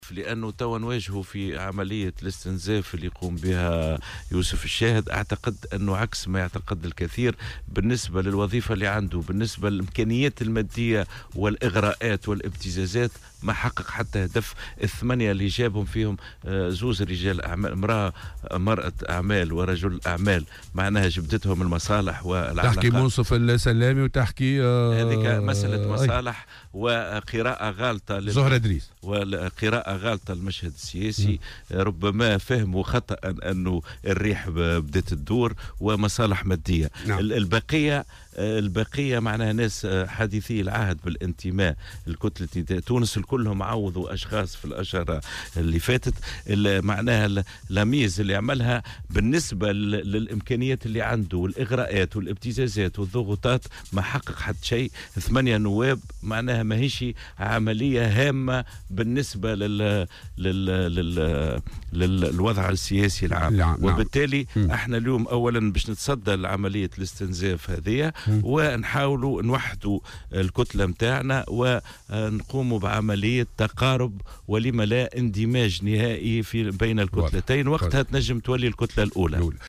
قال القيادي في حركة نداء تونس، رضا بالحاج، ضيف برنامج "صباح الورد" إن يوسف الشاهد قام باستنزاف الحزب وكتلته البرلمانية.